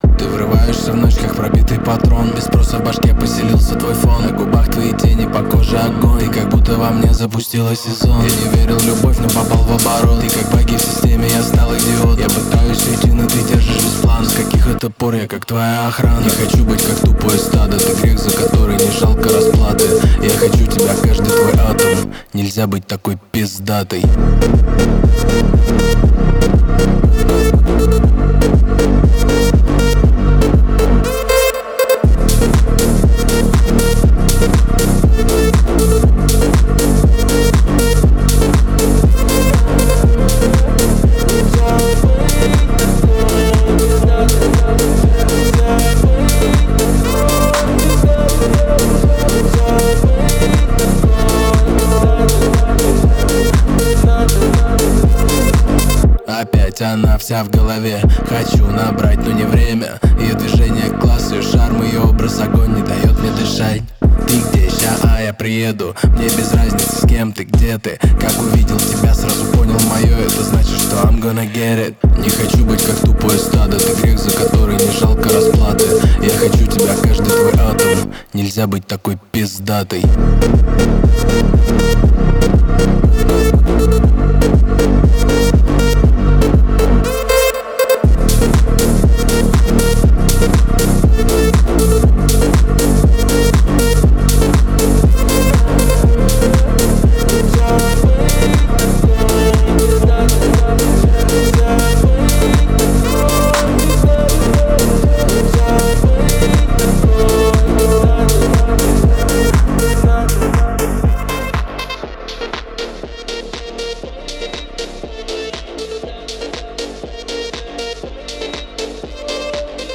Русский рэп 2025, Рэп